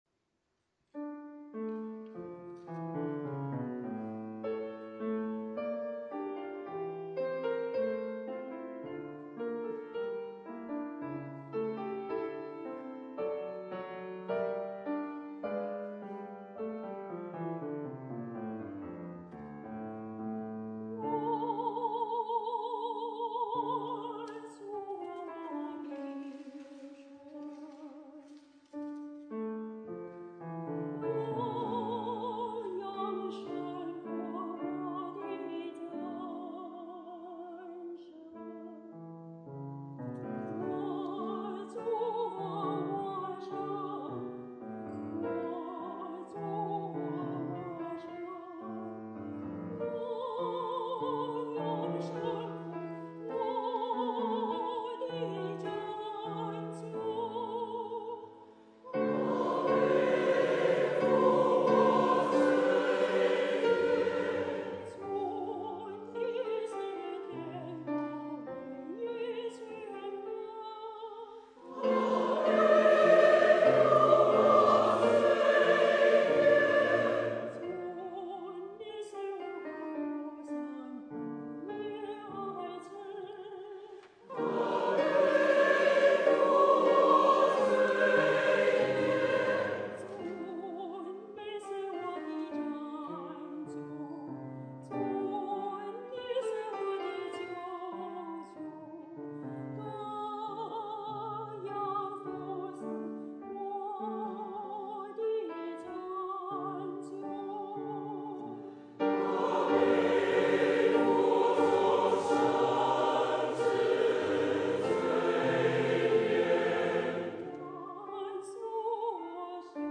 樂章 音部 現場錄音